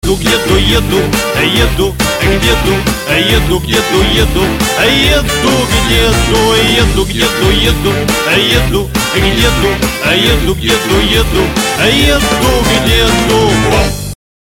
• Качество: 128, Stereo
смешные